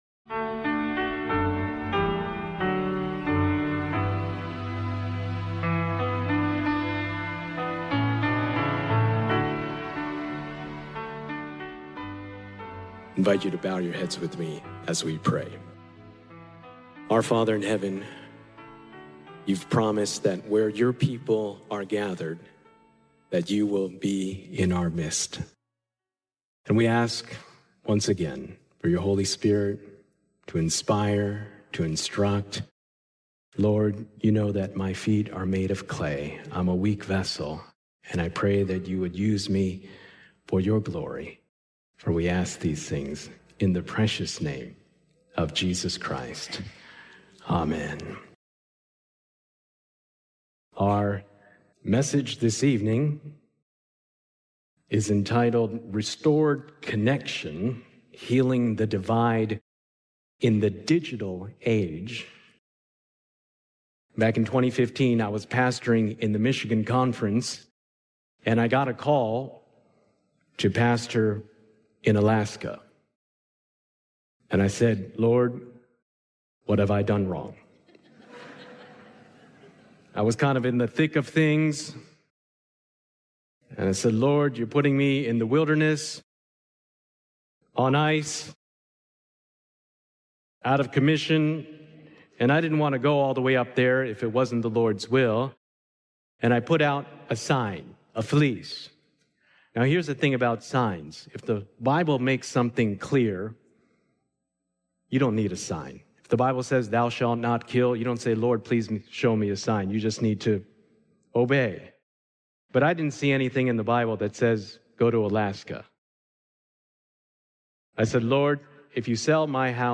This eye-opening talk exposes how social media and technology hijack our brains through dopamine-driven design, fueling addiction, anxiety, and sleeplessness. Blending neuroscience, real-world examples, and spiritual insight, it reveals how discipline, rest, and surrender to God can restore focus, freedom, and emotional balance in a digitally distracted world.